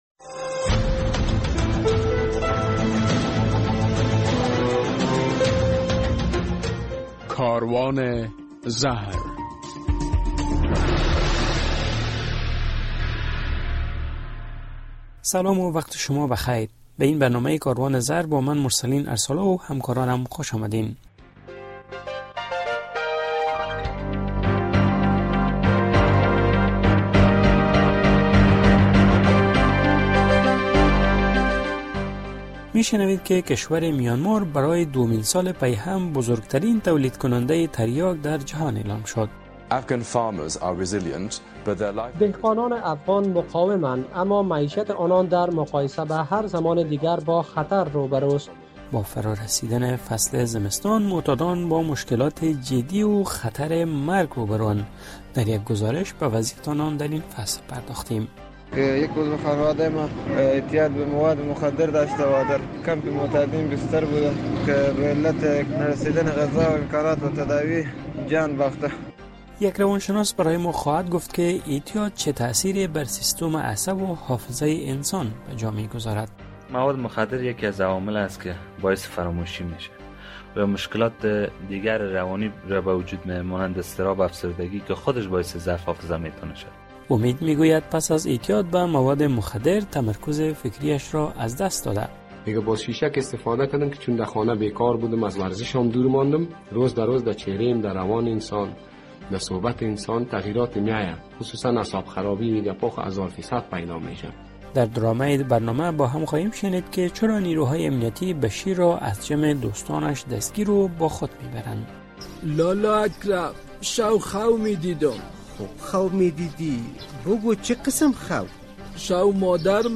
در این برنامه کاروان زهر می‌شنوید که میانمار برای دومین سال پیهم در کشت کوکنار از افغانستان پیشی گرفت، در یک گزارش می‌شنوید با سرد شدن هوا معتادان بی سرپناه با مشکلات جدی روبرو اند، در مصاحبه با یک روانشناس از وی در رابطه تاثیرات اعتیاد بر حافظه انسان پرسیدیم، در ادامه هم خاطرات زنده گی یک معتاد...